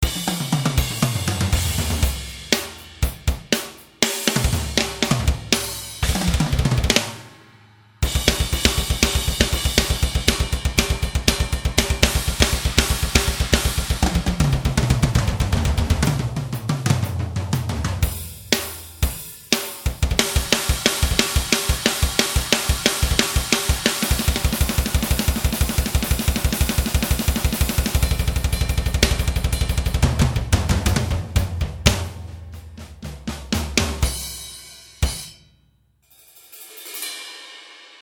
Son domaine à lui, c'est le metal !!!
- En collant les loops et autres patterns =